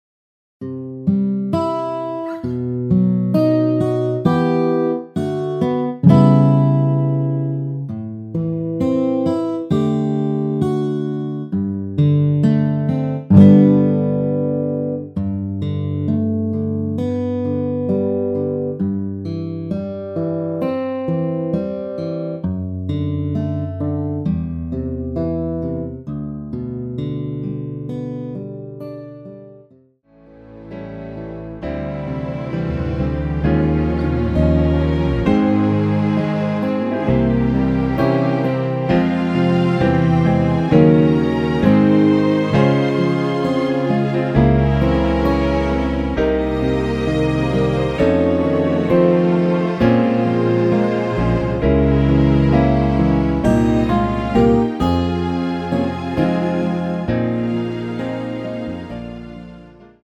원키에서(+8)올린 MR입니다.
앞부분30초, 뒷부분30초씩 편집해서 올려 드리고 있습니다.
중간에 음이 끈어지고 다시 나오는 이유는